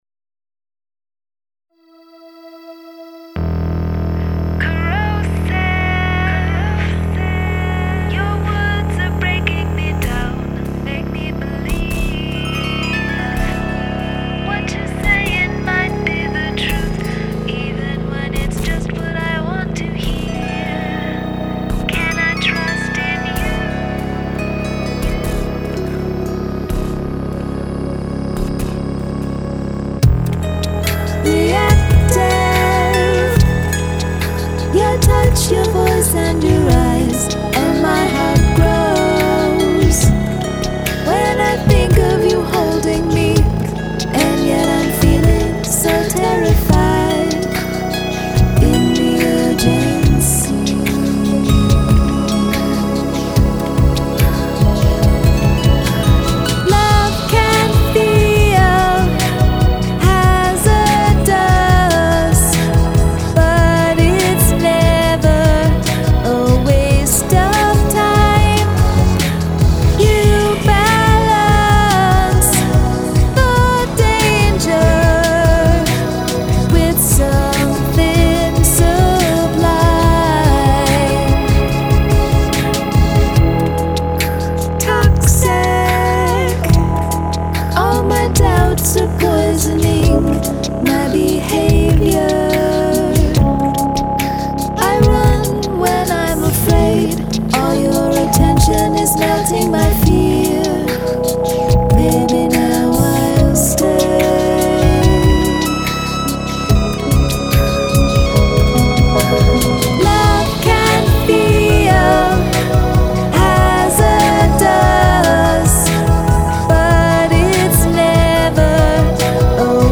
Beatbox